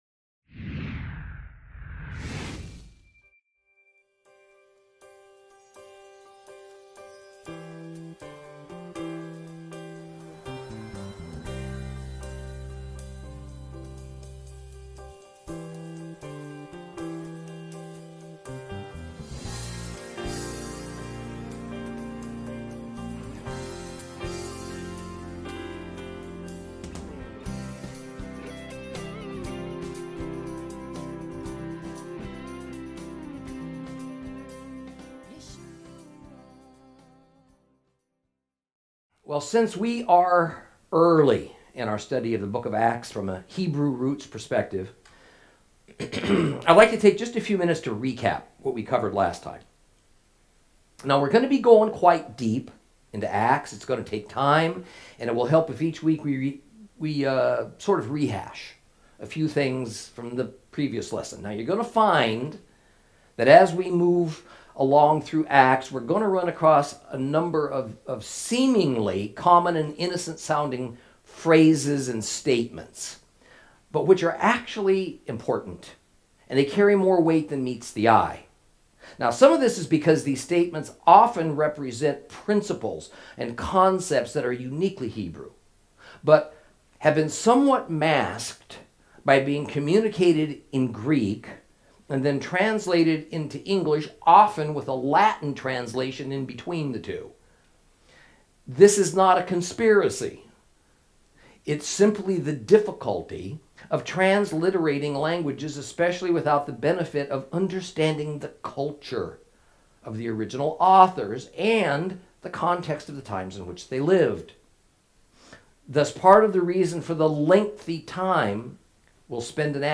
Lesson 3 Ch1 - Torah Class